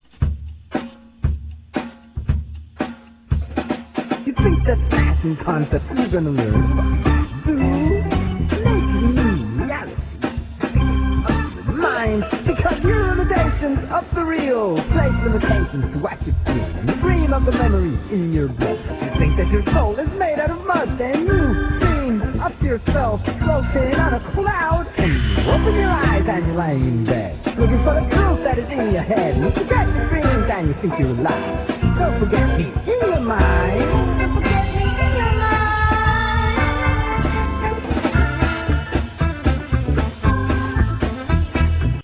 funk - rap